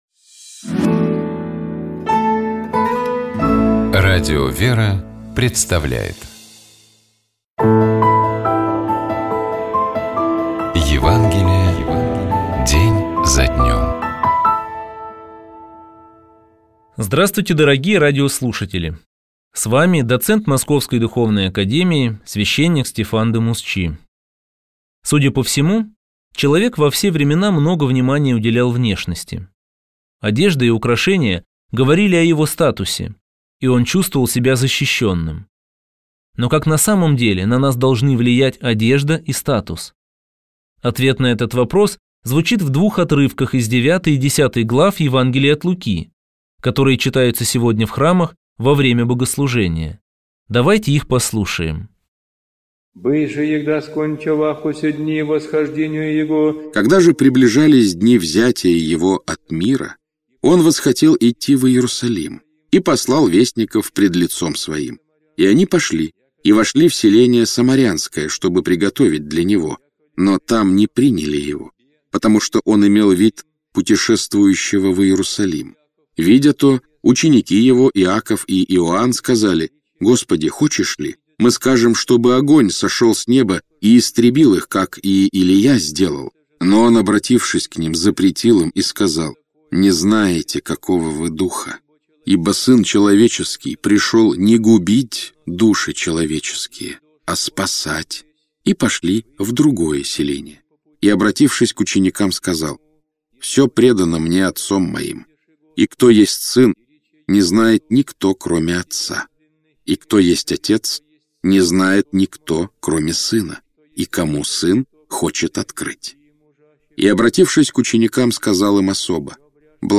Читает и комментирует